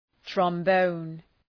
Προφορά
{trɒm’bəʋn}